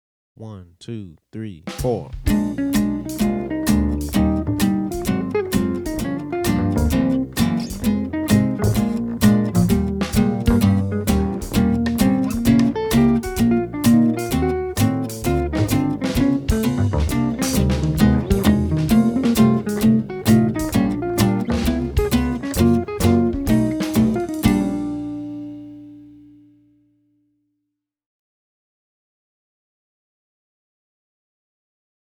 Méthode pour Guitare